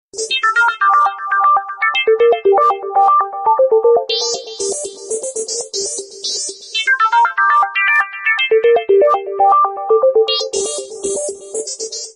cheerful, catchy and addictive melody